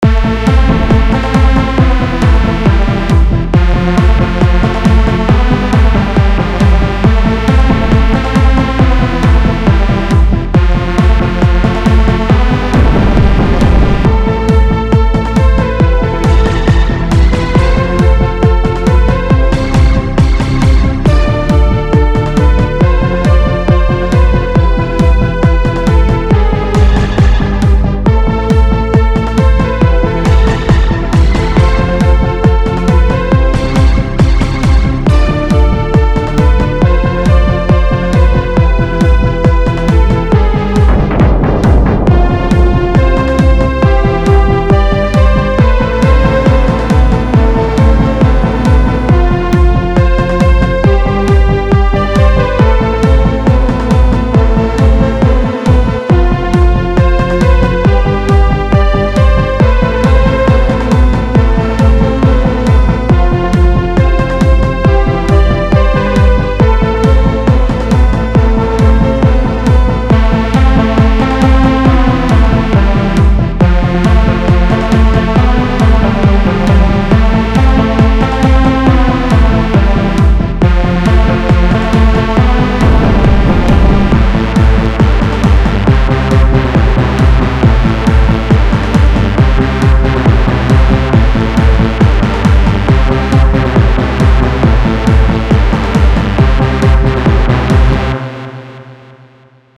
:sarcastic: Записались "биг-бэндом", уложили все 12 дудок (саксофонист все 5 партий, трубач все 4, тромбонист все 4).
Саксы слева, трубы\т-боны - справа.
3 дудки, гитара, клавиши, бас, барабаны Вложения n25_5_264252.mp3 n25_5_264252.mp3 3,8 MB · Просмотры